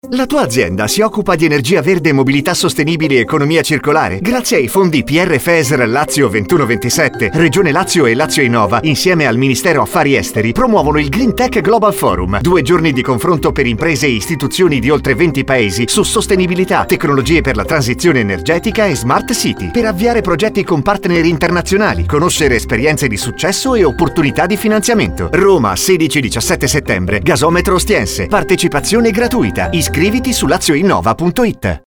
Spot_GreentechGlobalForum_RDS.mp3